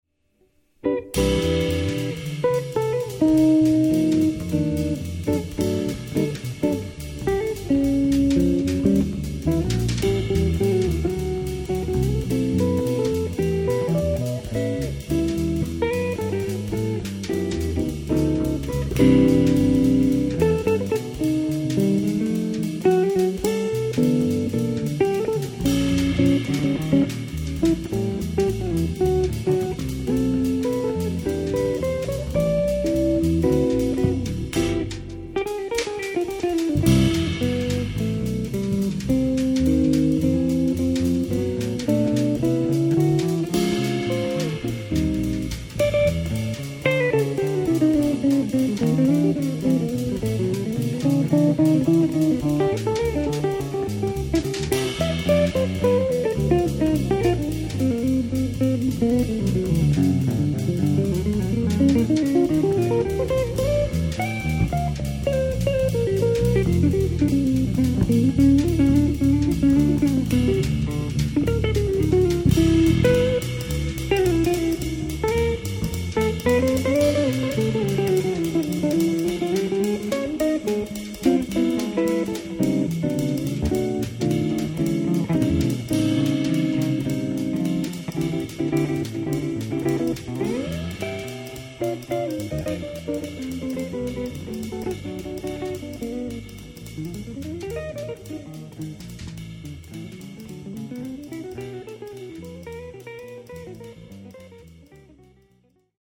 Straight Swing